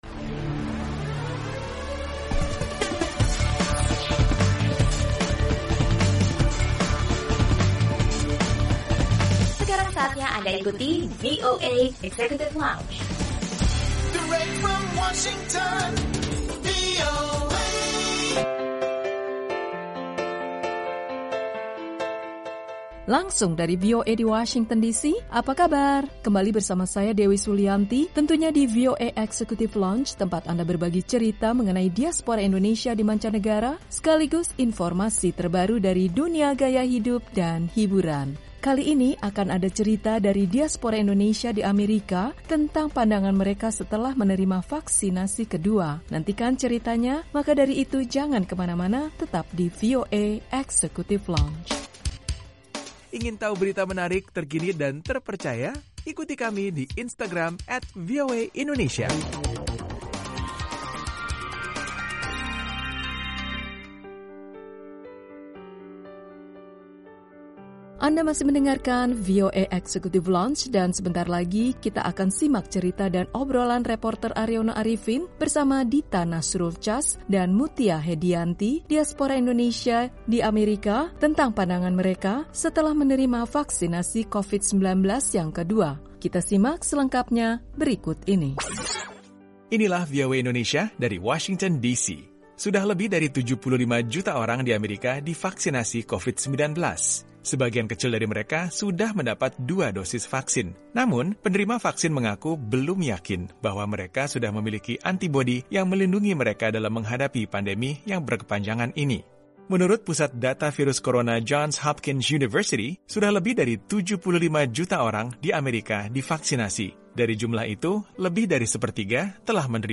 Cerita dan obrolan reporter